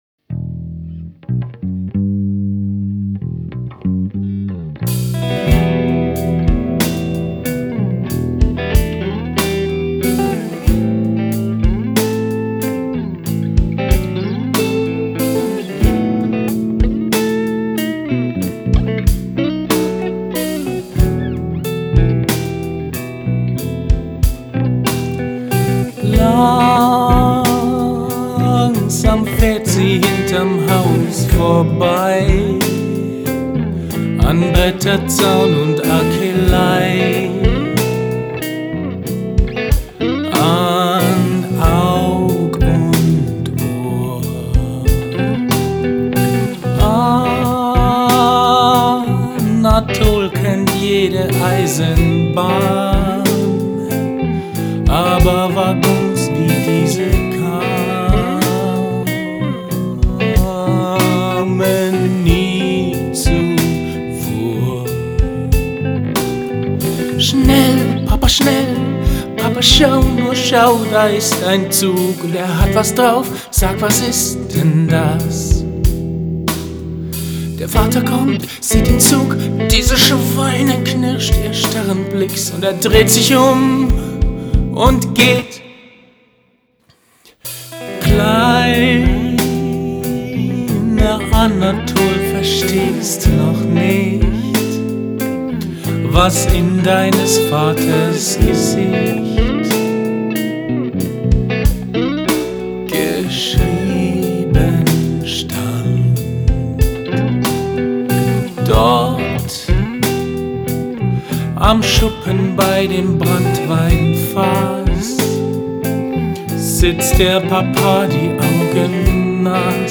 Hier mal bis zur zweiten Strophe.
Das ist nur ein Demo für die "Kollegen", deren Hobby anscheinend darin besteht, die Arbeit und Gefühle anderer Musiker und Texter so richtig in den Dreck zu treten.